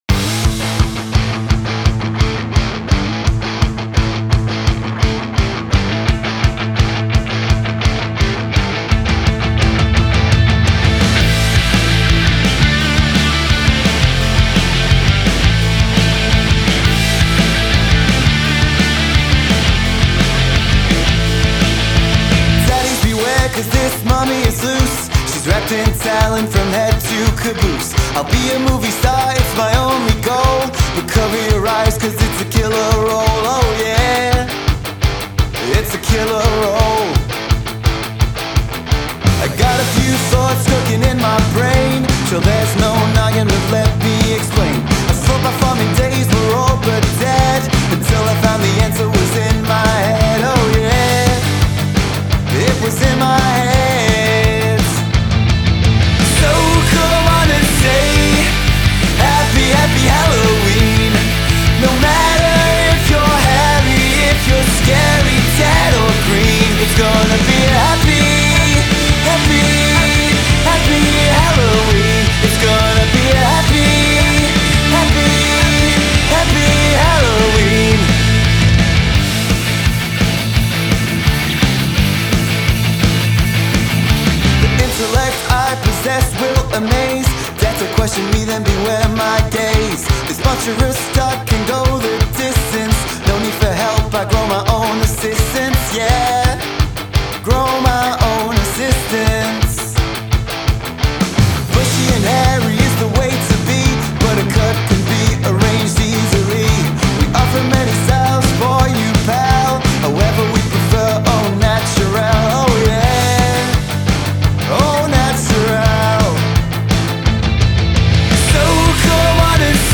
Inspired by an ecletic mix of pop and alternative rock